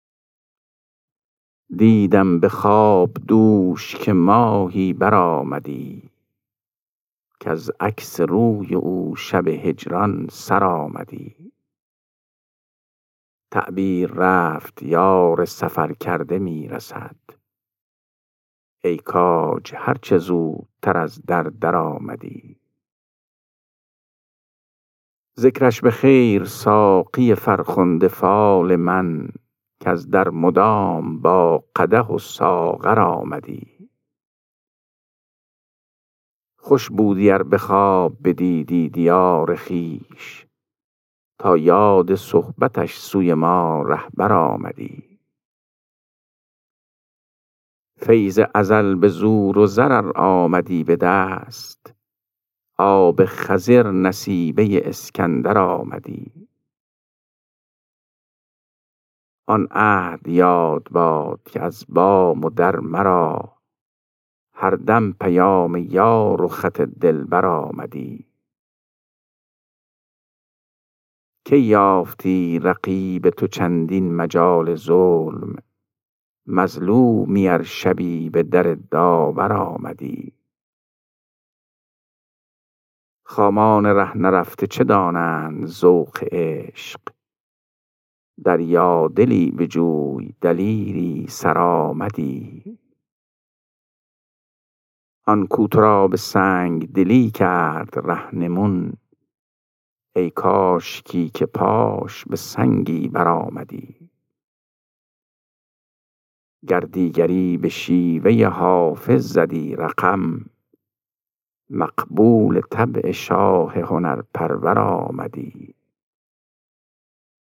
خوانش غزل شماره 439 دیوان حافظ